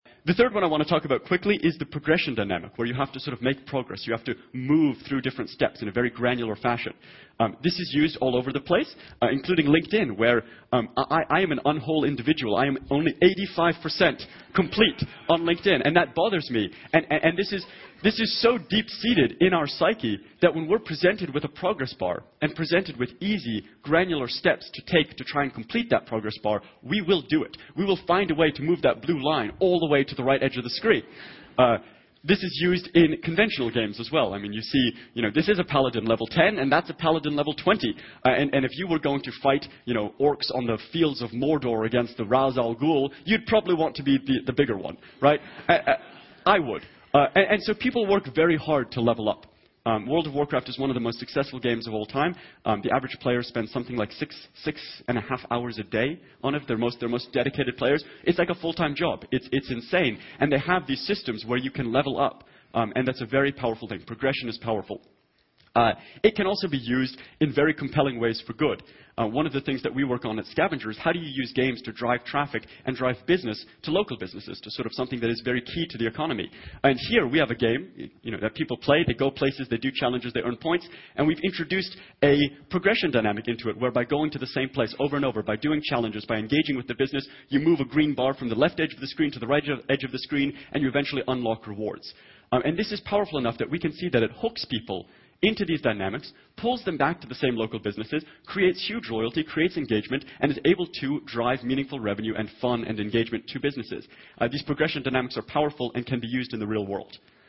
TED演讲:最棒的游戏社交圈(5) 听力文件下载—在线英语听力室